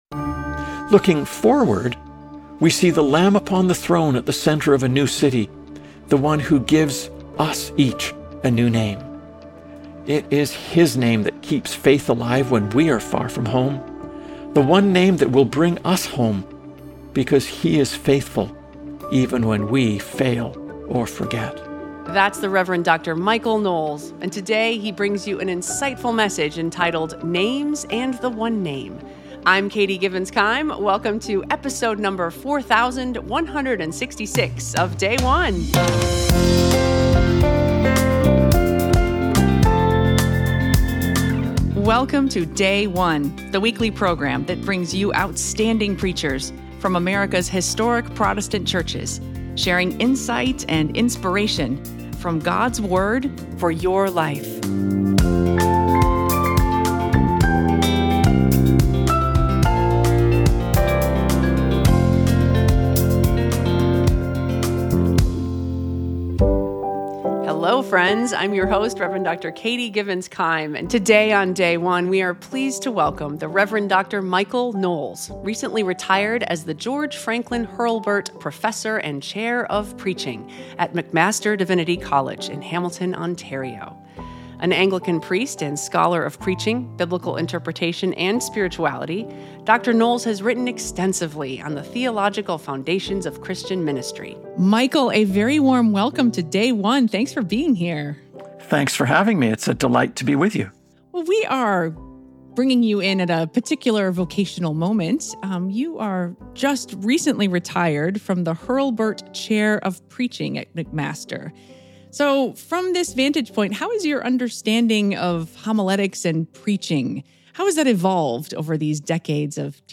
Read the Sermon Transcript: